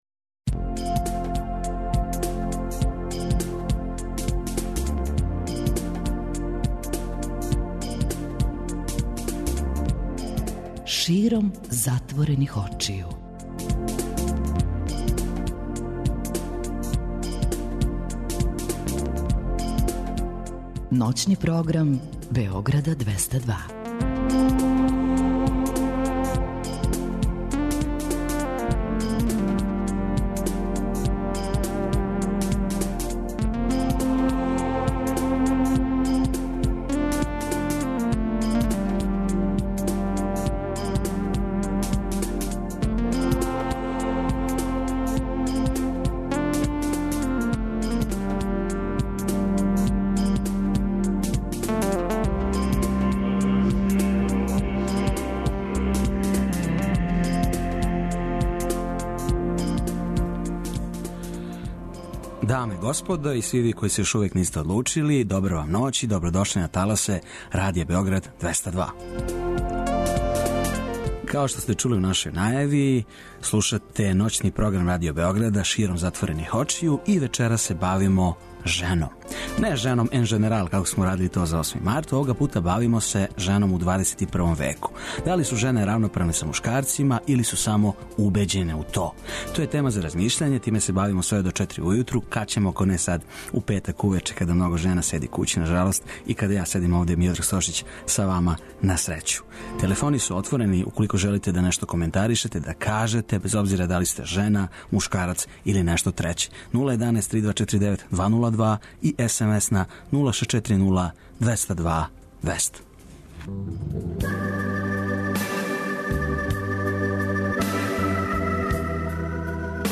Кроз доста забаве али на обострани рачун бавићемо се овом темом укључујући као саговорнице жене које се баве новинарством, писањем, хумором и многим другим до скора мушким занимањима.